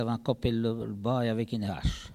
Elle provient de Saint-Urbain.
Locution ( parler, expression, langue,... )